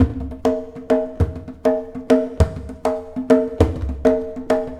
It features a mixture of field and studio recordings and programming for
Tabla,
Oriental Percussion,